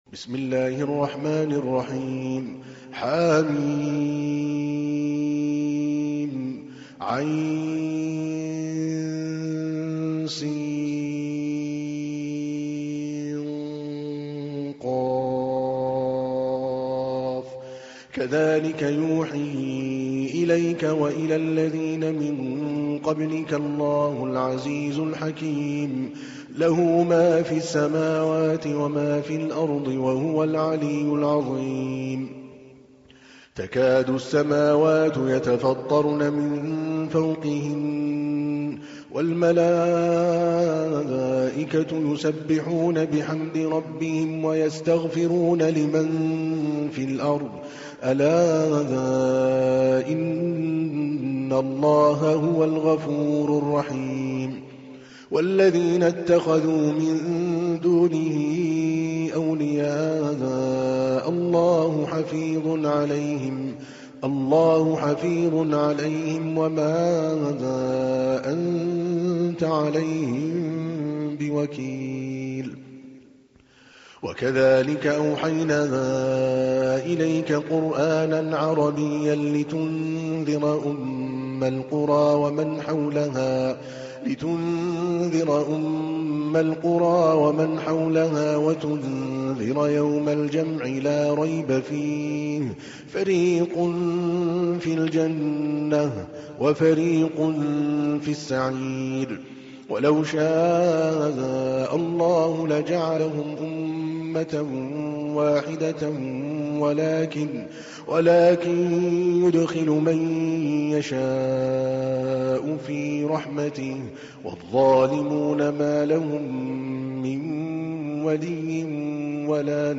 تحميل : 42. سورة الشورى / القارئ عادل الكلباني / القرآن الكريم / موقع يا حسين